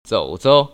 [zŏu‧zou] 조오조우  ▶